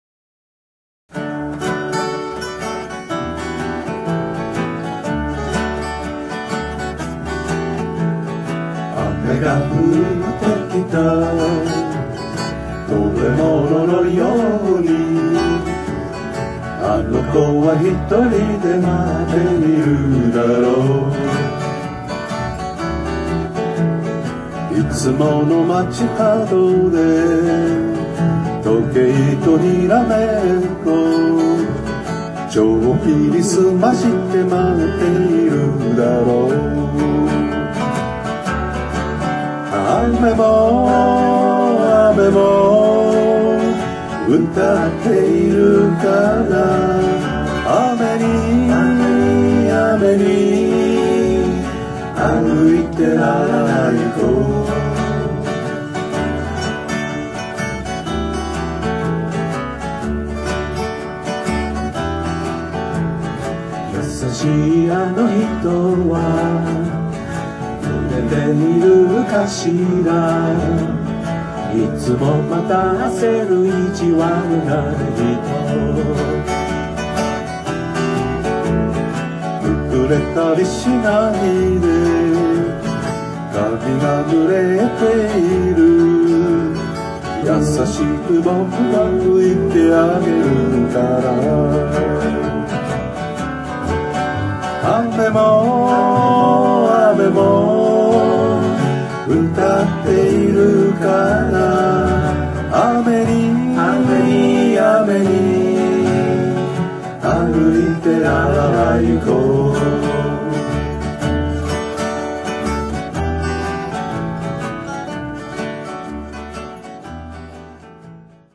■２００３年６月１日（日）練習■パルコ　６月１５日の横浜そごう